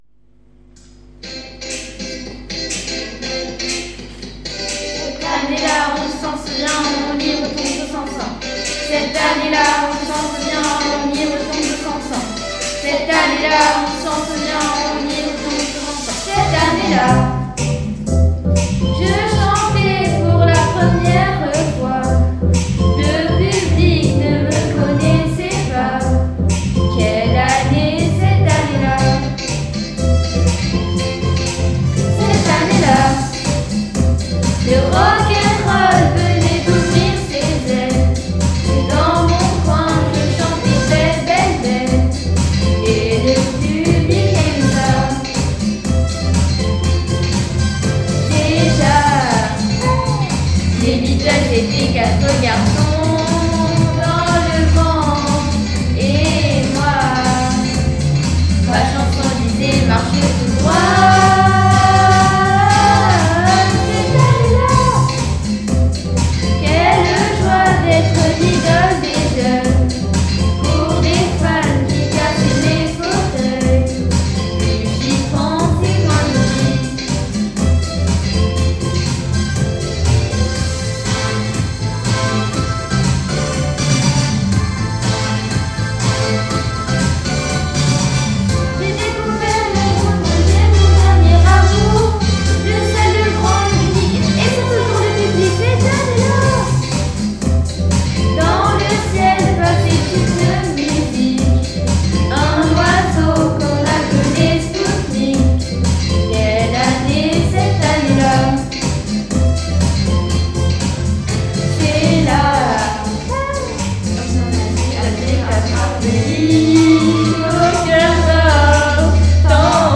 Avec seulement 6 voix